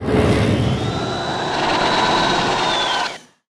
File:Antra roar trailer.ogg
Antra_roar_trailer.ogg